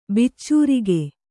♪ biccūrige